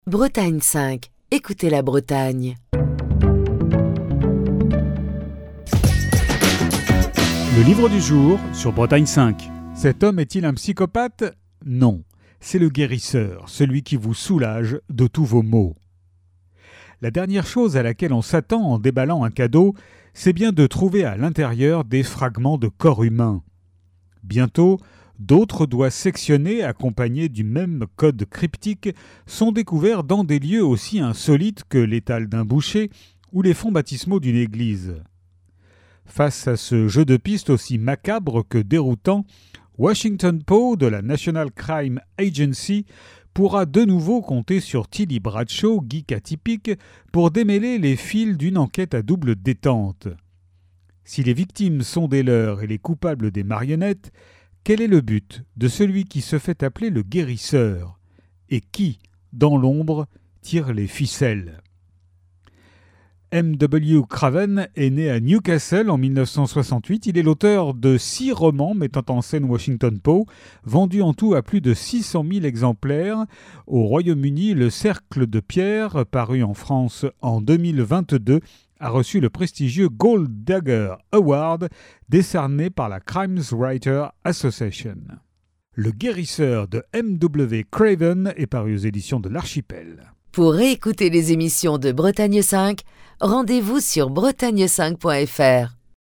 Chronique du 11 octobre 2024.